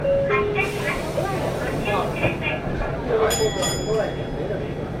qpg-lv234n3-shinto-bus-bell.mp3